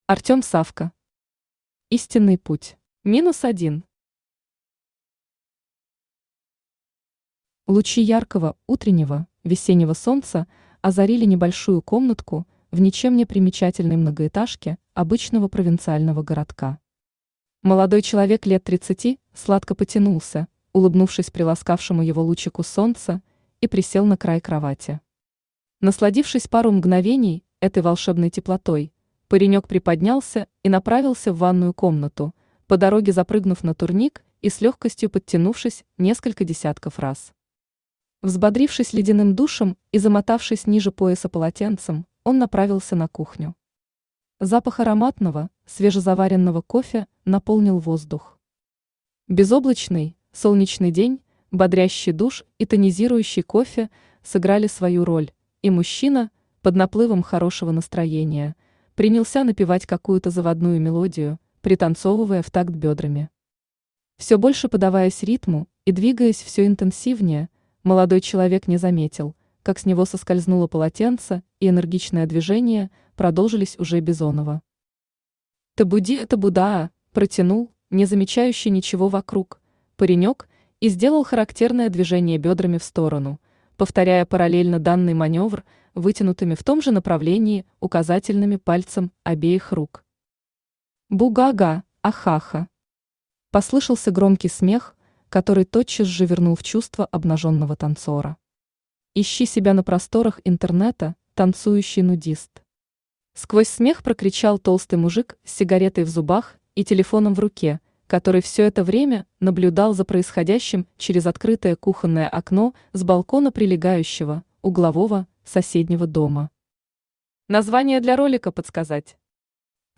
Аудиокнига Истинный путь | Библиотека аудиокниг
Aудиокнига Истинный путь Автор Артём Савко Читает аудиокнигу Авточтец ЛитРес.